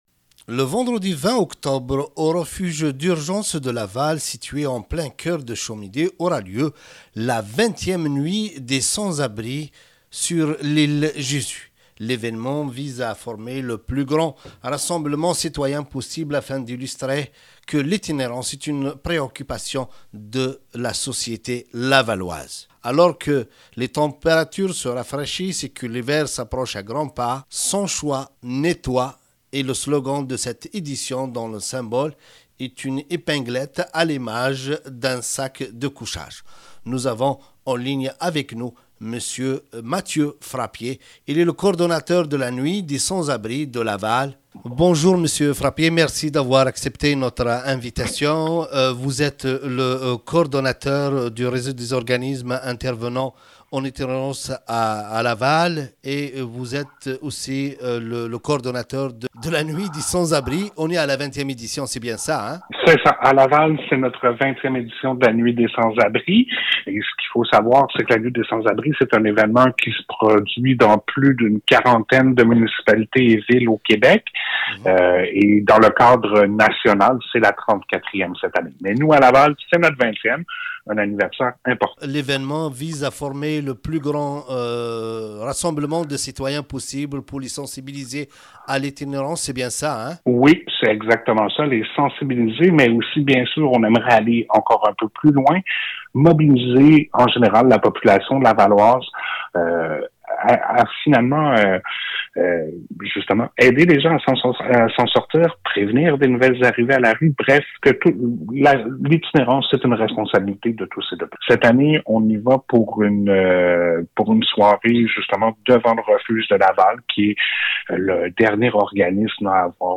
Nous avons en entrevue